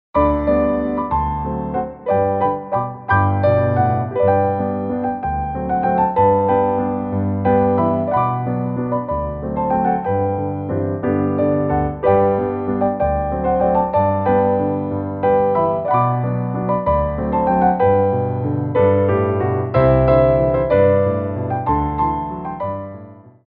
Battement Tendus /
Grand Battements
3/4 (8x8)